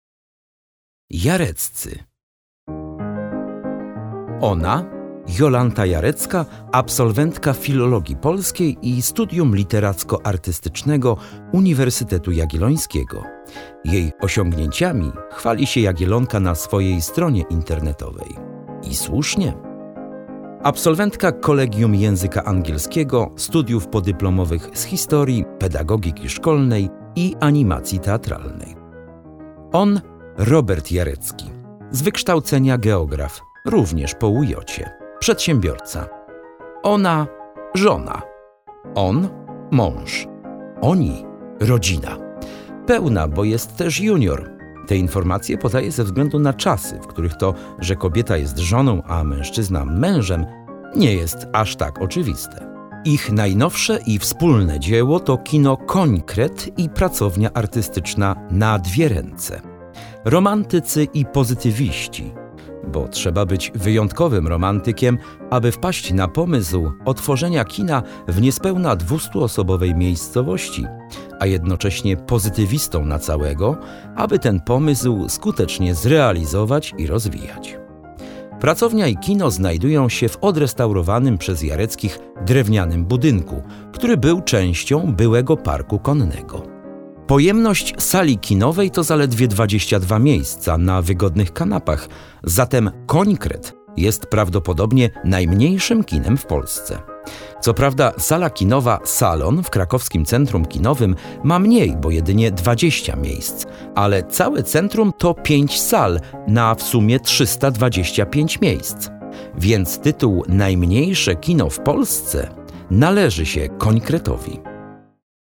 Audiobook wydawnictwa Promatek jest propozycją alternatywną, ponieważ książka jest dostępna także w wersji tradycyjnej – „papierowej”. Bogato ilustrowana dźwiękowo. Można tu znaleźć zarówno „bieszczadzką ciszę” jak i zgiełk wielkiego miasta.